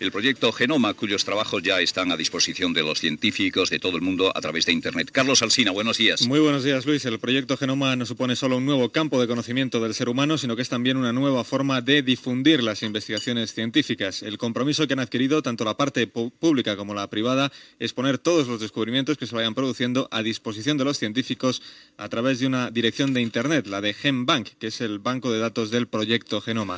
Conversa de Carlos Alsina amb Luis del Olmo sobre els possibles oients de la ràdio a primera hora del matí i notícia sobre el projecte genoma
Info-entreteniment